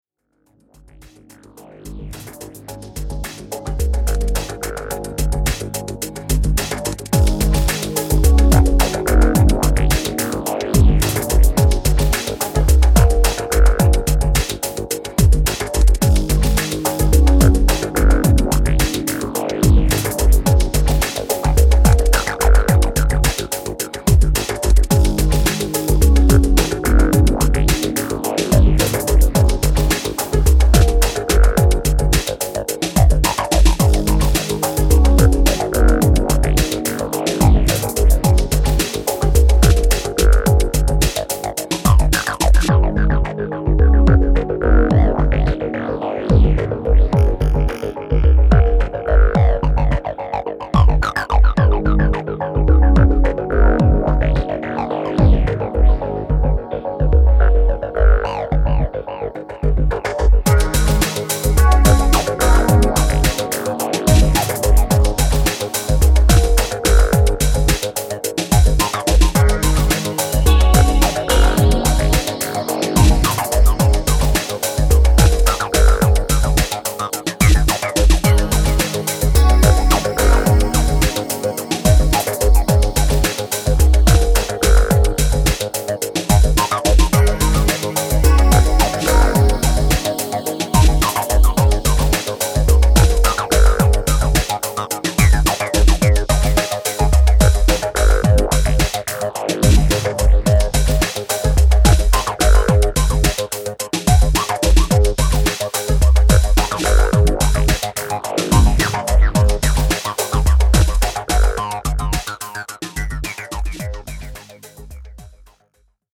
A special downtempo, trance, breaky Mini LP
Electronix Trance